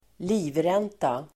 Ladda ner uttalet
Uttal: [²l'i:vren:ta]